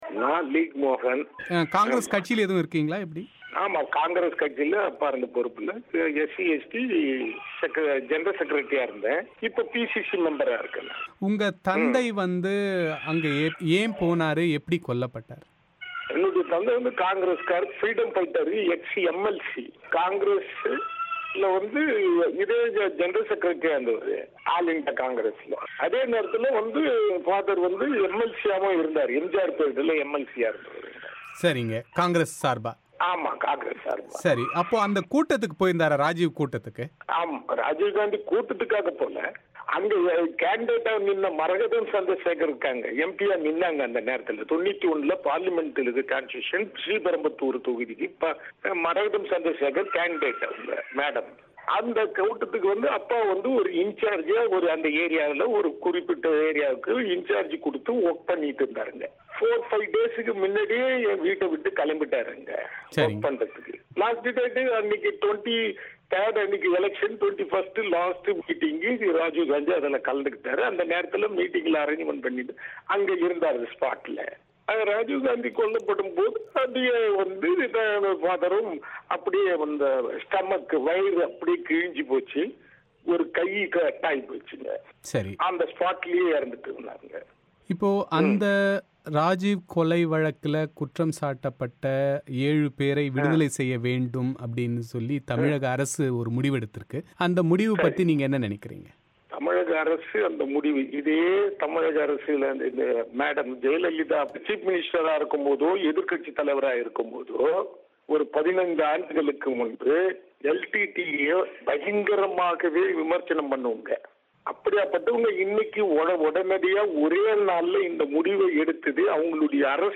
இந்த ஏழுபேரை விடுவிக்கும் தமிழக அரசின் முடிவை எதிர்ப்பது ஏன் என்பது குறித்து அவர் பிபிசி தமிழோசையிடம் விளக்கும் அவரது விரிவான செவ்வி.